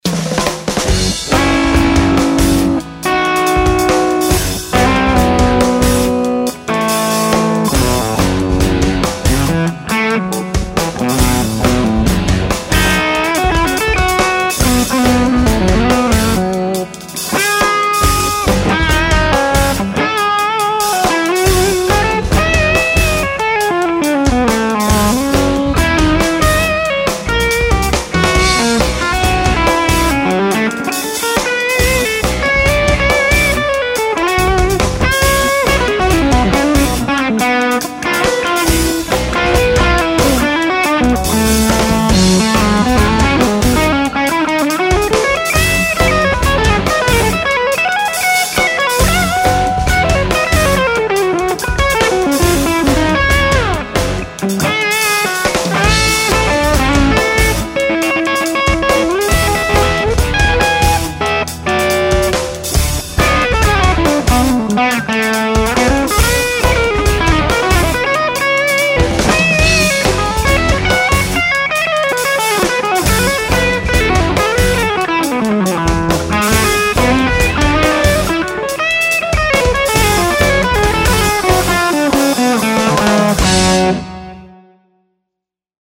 Moved the SM57 into a much better spot.
This one is really good and of all the excellent clips you've posted lately, the character in this sound sounds like it would jump out of the mix in a live situation without having to play way too loud.
That's gotta be the smoothest most subtle hair I've yet heard.
It does darken things so the amp is tweaked to provide the correct brightness levels when using the loop.
I added a touch of verb and delay to the dry recorded signal after the fact.
Dude, that tone is just about IDEAL! 2nd clip is better - clearer.